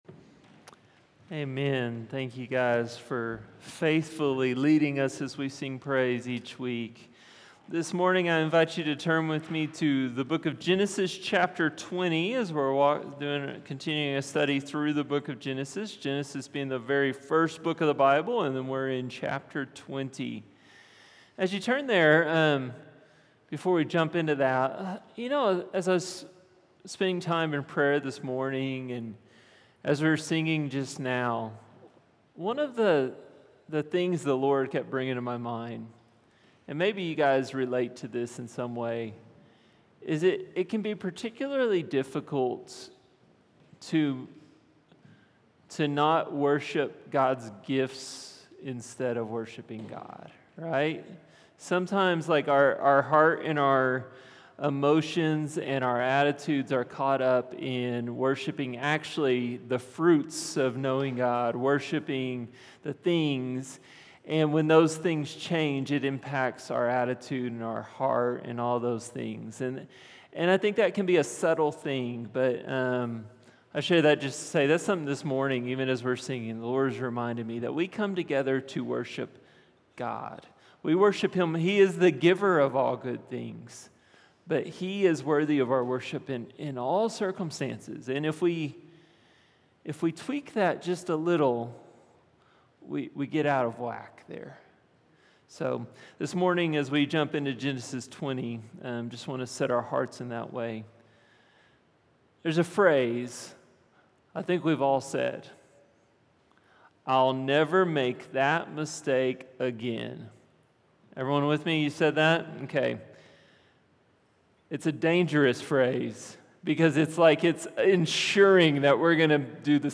Sermons | HopeValley Church // West Jordan, UT